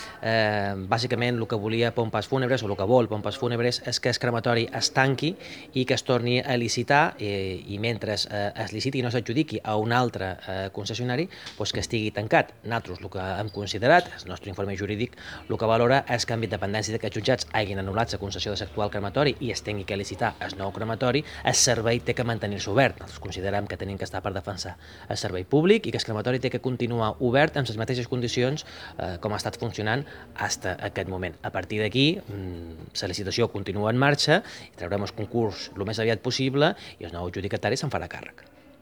Corte de voz Ana Costa- Exposición Retablo Jesús